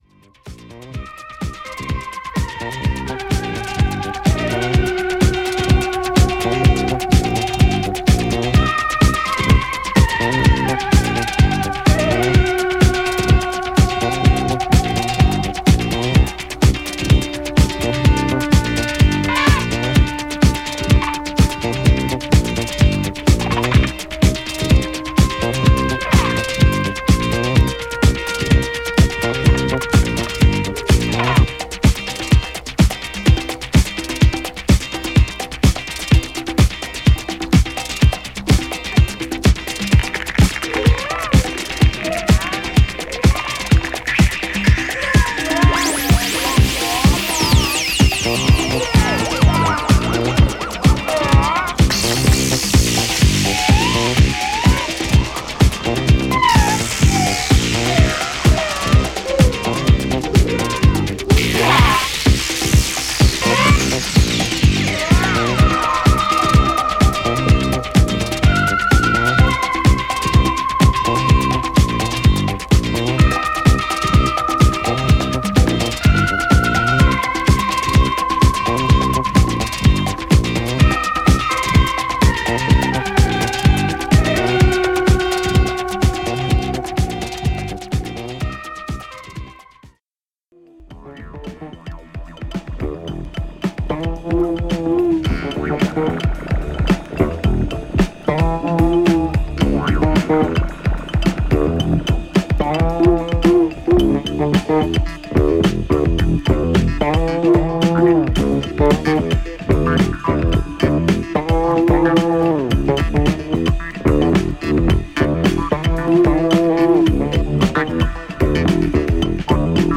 ELECTRONIC
SSPACE FUNK BAND